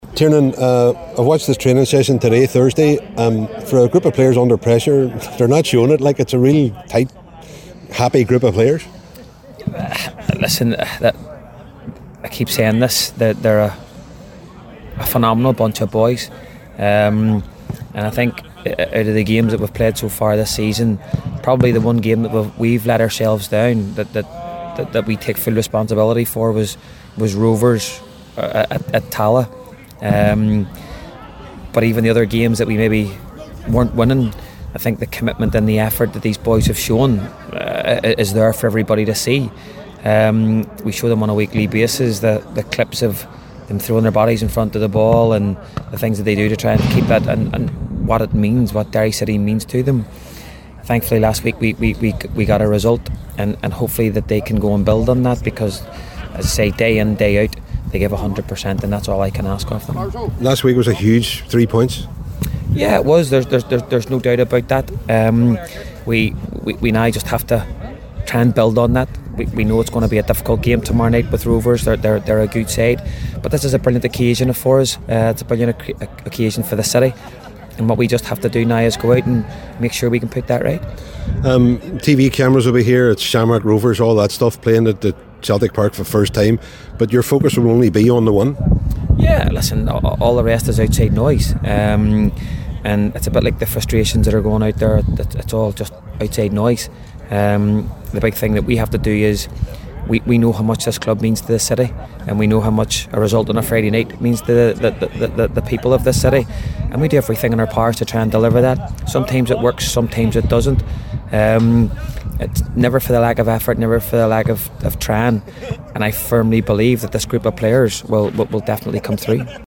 a press event in the lead up to the game